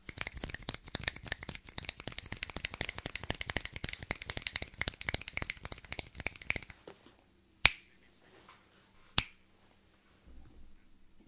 3架螺旋桨货机
描述：四架螺旋桨货运飞机在le Bourget上空的演变，用744T和shure VP88立体声麦克风44.1khz 16 bits wave
Tag: 字段 记录 立体声